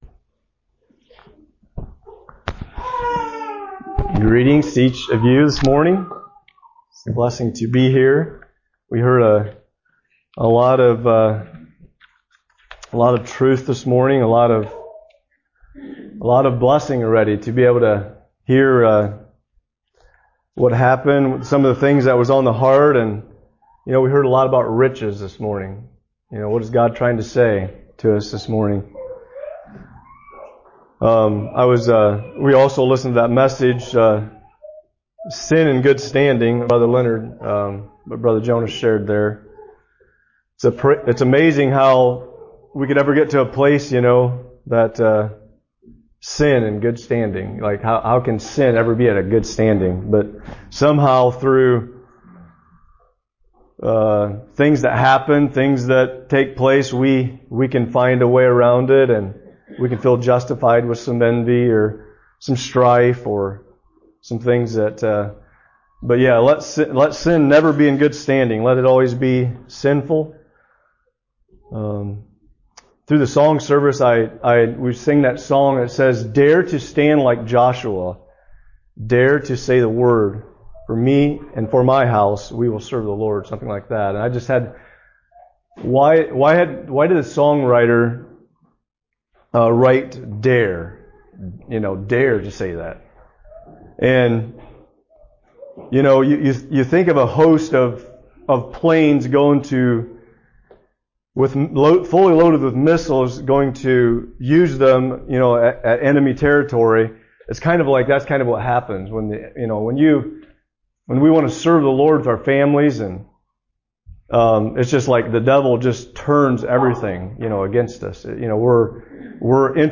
2025 Sermons | Be Strengthened and Encouraged by God's Word
Listen to and download sermons preached in 2025 from Shelbyville Christian Fellowship.